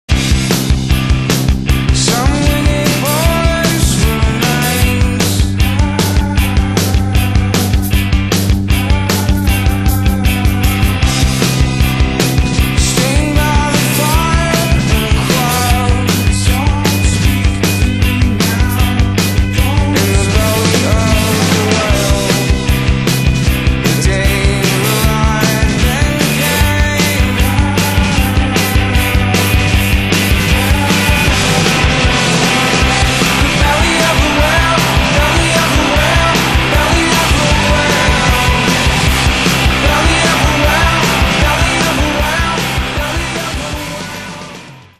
guitar, vocals
drums
keyboards, backing vocals, guitar
bass guitar